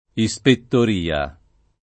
[ i S pettor & a ]